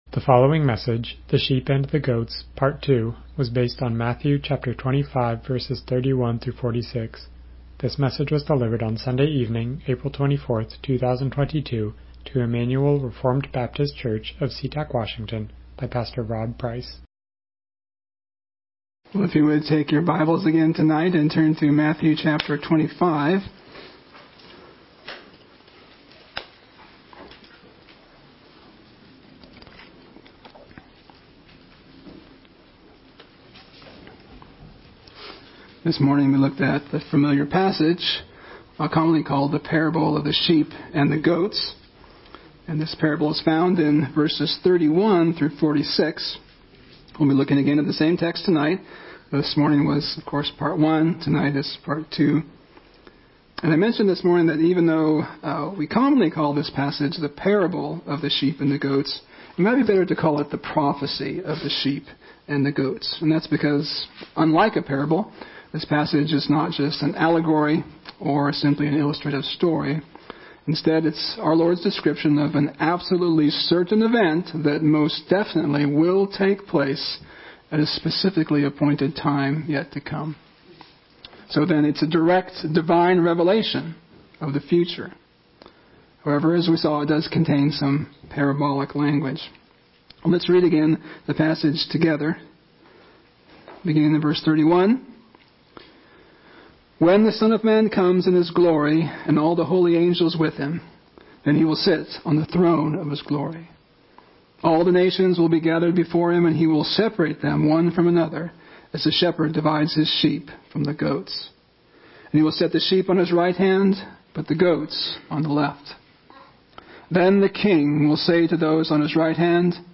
Passage: Matthew 25:31-46 Service Type: Evening Worship « The Sheep and the Goats